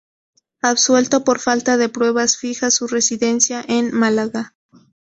/ˈfalta/